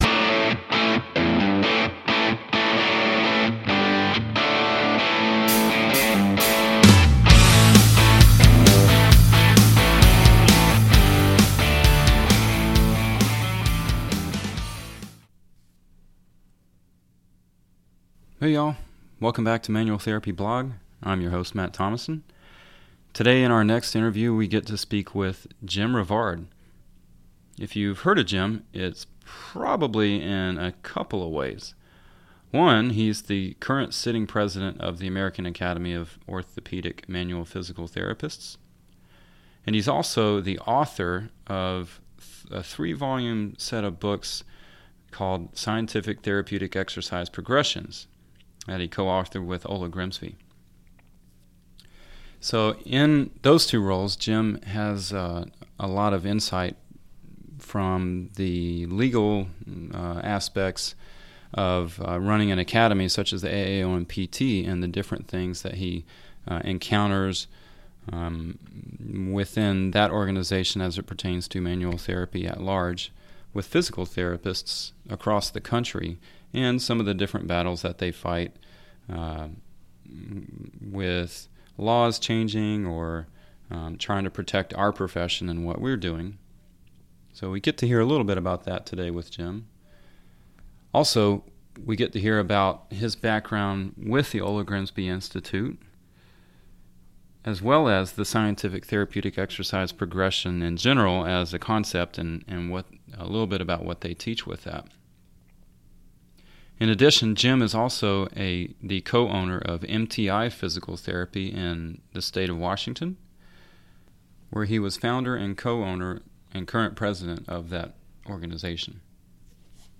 So, I hope you enjoy this conversation.